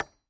金属 " 金属撞击短链
描述：短时快速打在链环上
标签： 金属 命中 链路
声道立体声